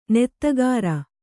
♪ nettagāra